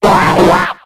Audio / SE / Cries / PSYDUCK.ogg